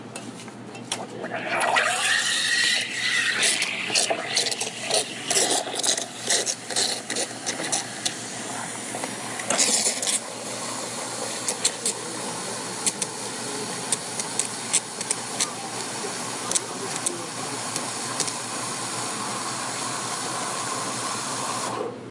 餐厅环境音
描述：餐厅环境音，在吉尔罗伊录制，Longhouse餐厅。
标签： 早餐 食物 餐厅 咖啡店 用餐
声道立体声